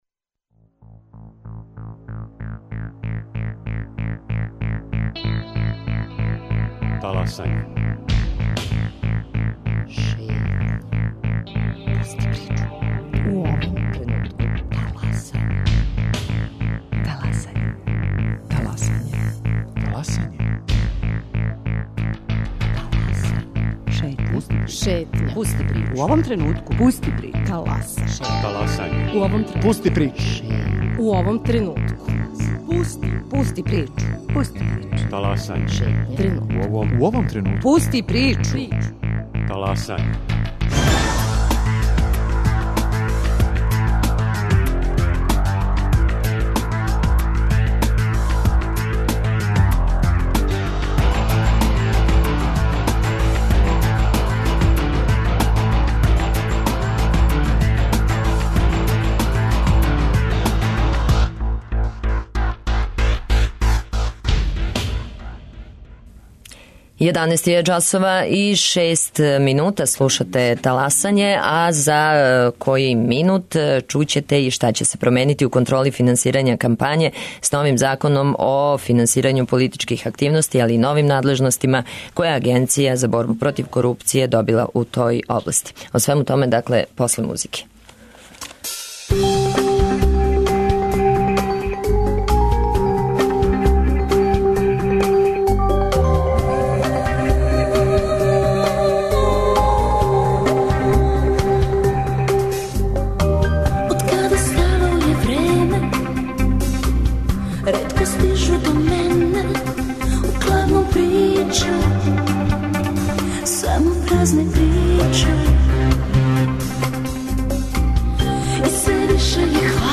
Гости: Зоран Стојиљковић, председник Одбора Агенције за борбу против корупције